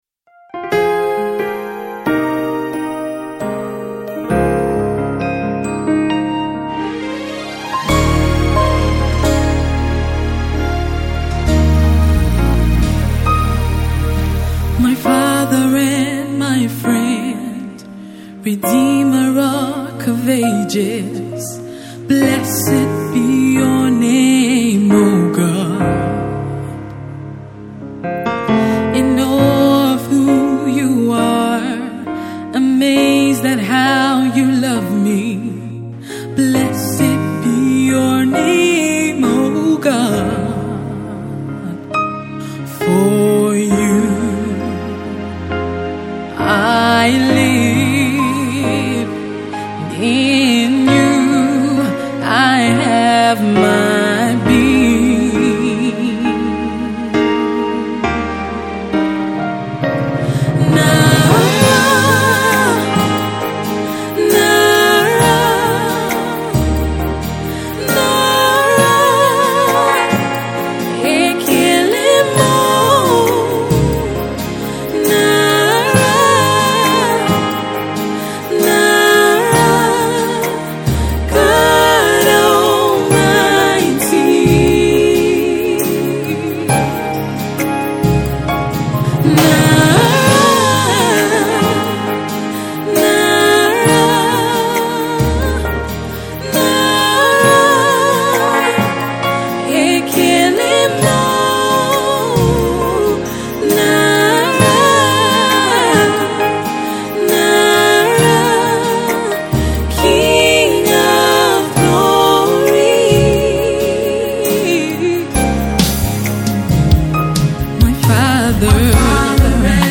timeless worship song of thanksgiving